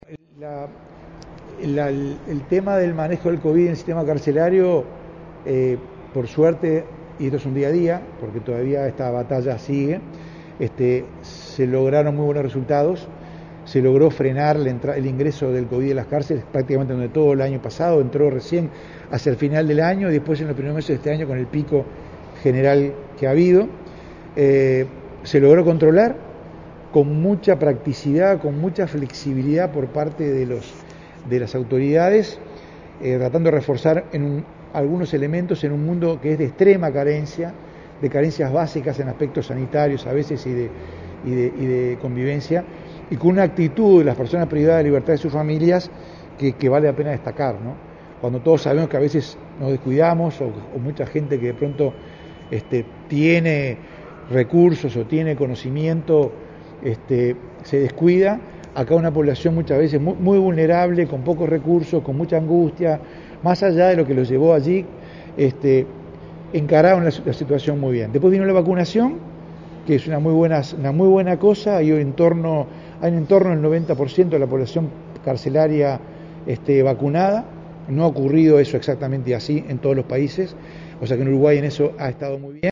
El comisionado parlamentario penitenciario, Juan Miguel Petit, dijo en rueda de prensa que «el 90% de la población carcelaria está vacunada contra el Covid-19″ y sumó «que no ha ocurrido así en ningún otro país».